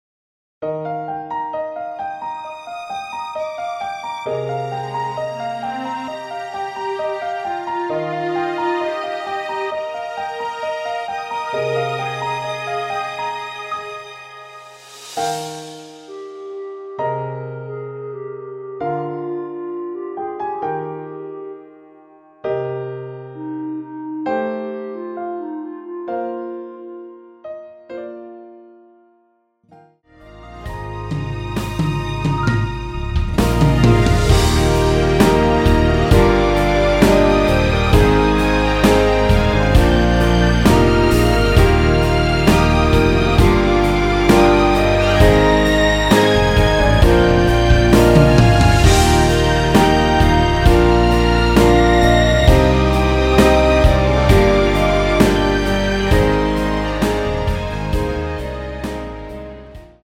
남자키 멜로디 포함된 MR 입니다.(미리듣기 참조)
Eb
앞부분30초, 뒷부분30초씩 편집해서 올려 드리고 있습니다.
(멜로디 MR)은 가이드 멜로디가 포함된 MR 입니다.